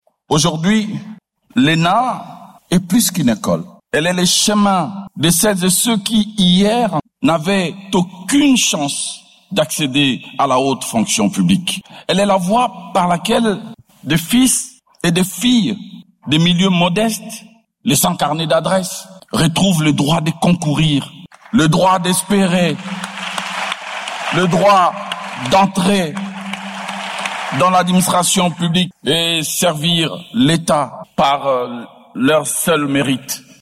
Organisée au Centre culturel et artistique d’Afrique centrale, cette cérémonie a coïncidé avec la célébration du dixième anniversaire de l’École nationale d’administration.